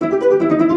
new pickup sound.
magic-hands.wav